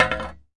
描述：将一把园艺锄头放在它的手柄底部，大约5英寸高。 用Tascam DR40录制。
Tag: 下降 晃动 冲击 木材 拨浪鼓 打击乐器 金属 金属 命中 冲击